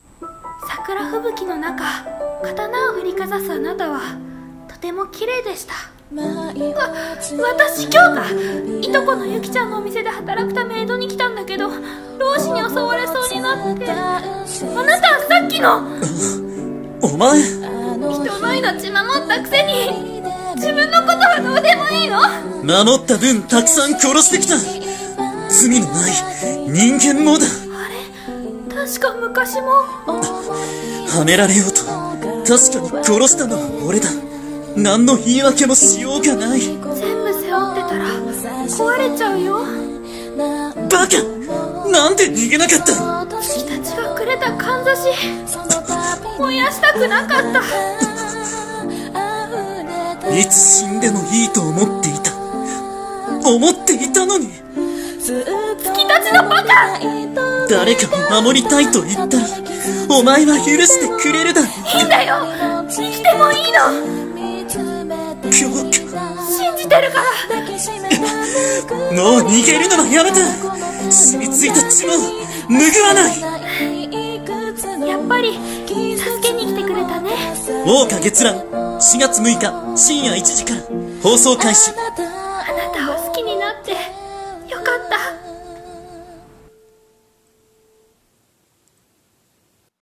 【アニメ告知CM風声劇台本】桜花月乱【２人声劇】